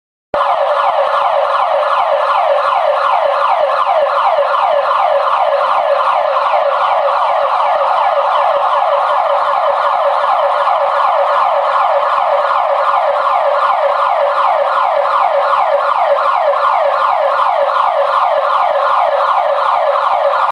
1. Alarma de oficina
Alarma-oficinas-1.mp3